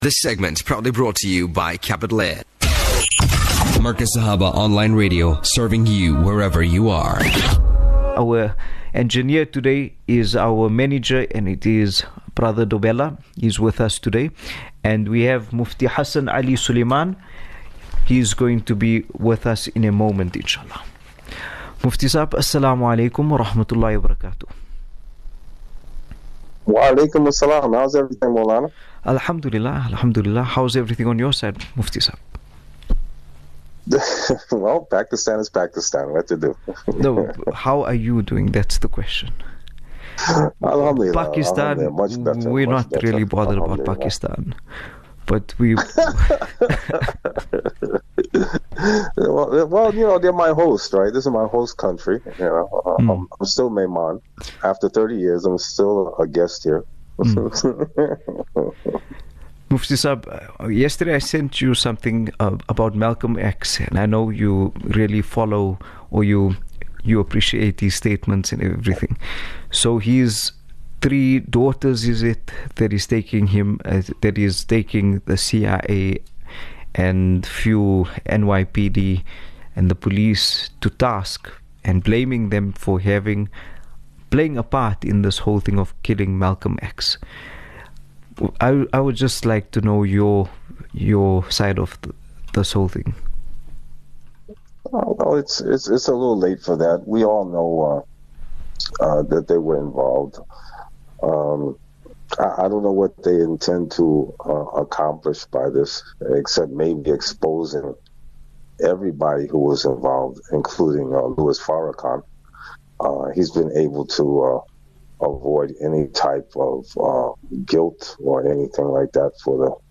18 Nov 18 November 2024 - Fascinating discussion on Muslims Living as Muslims